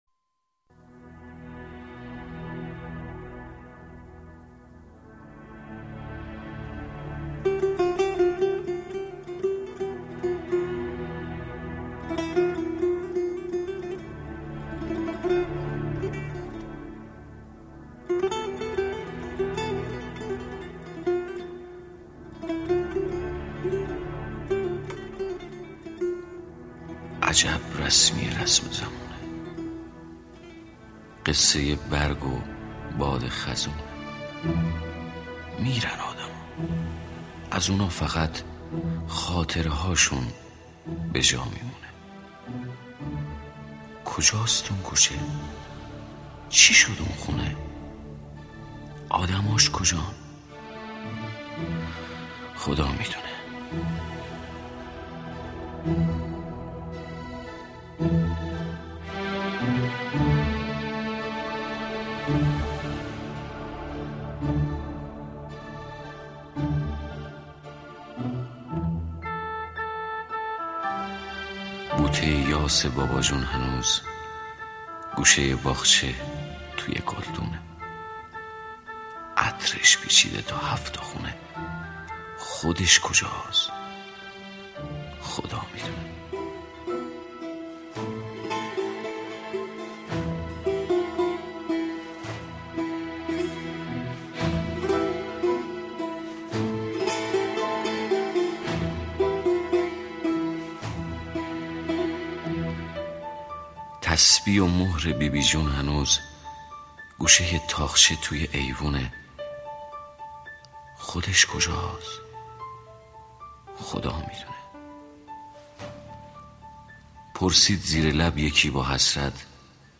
دانلود دکلمه رسم زمونه با صدای پرویز پرستویی
گوینده :   [پرویز پرستویی]